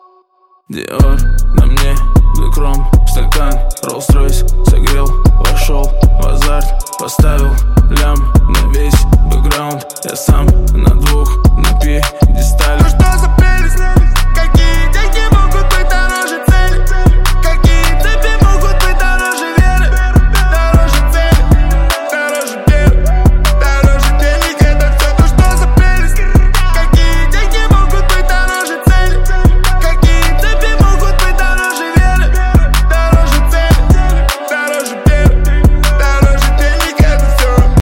• Качество: 128, Stereo
Хип-хоп